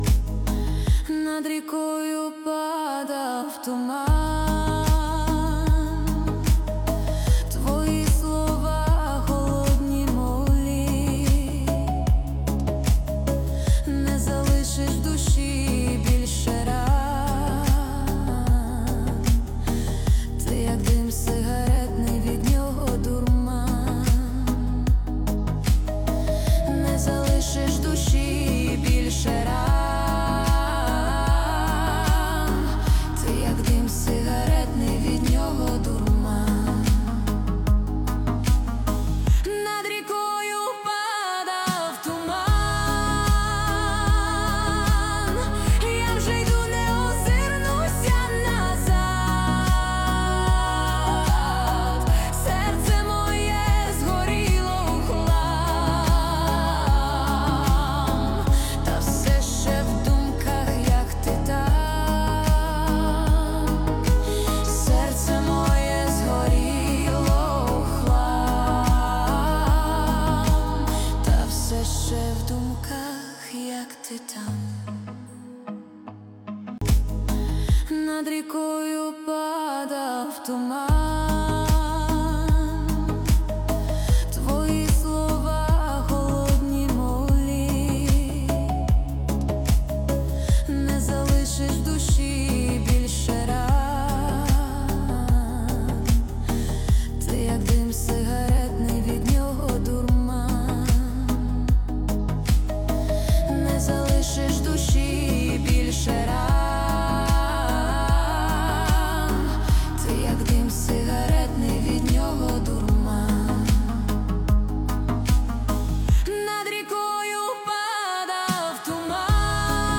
Качество: 320 kbps, stereo
Украинские писни 2025